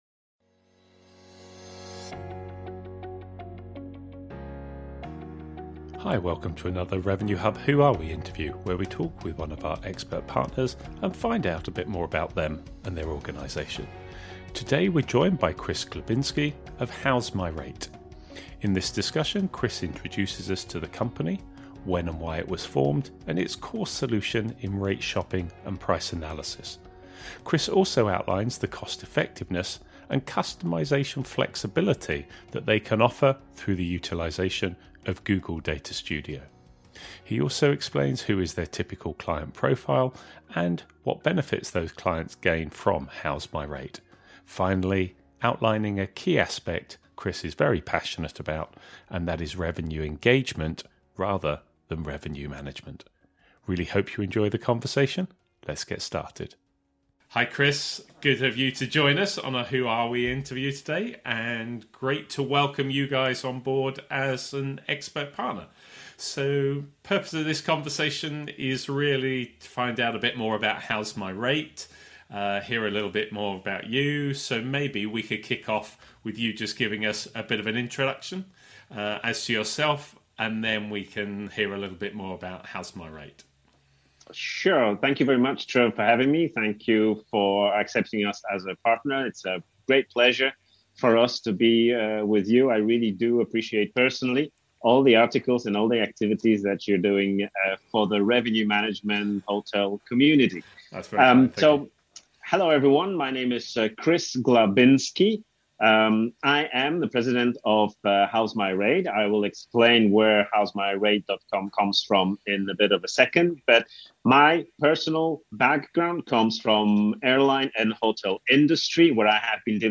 In this Who Are We interview we are joined by: